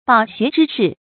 飽學之士 注音： ㄅㄠˇ ㄒㄩㄝˊ ㄓㄧ ㄕㄧˋ 讀音讀法： 意思解釋： 飽學：學識淵博。指學識淵博的人。